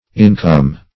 Income \In"come\, n.